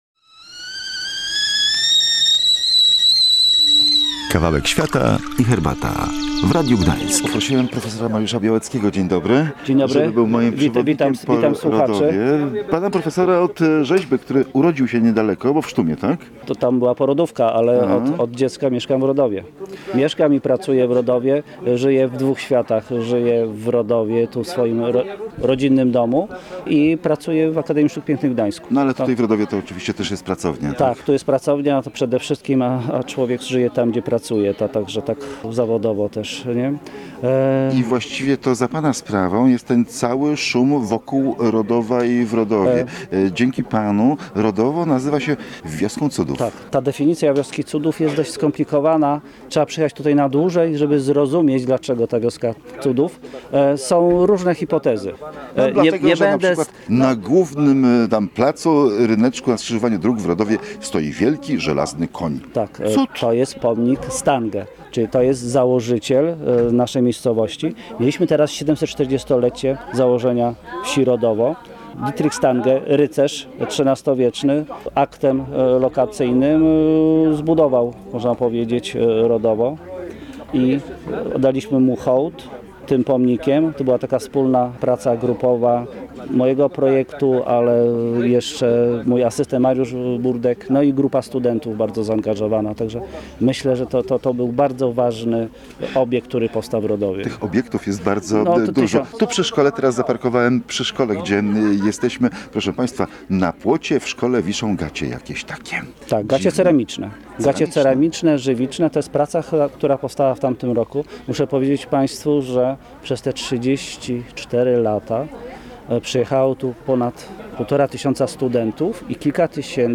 podczas otwarcia 34 pleneru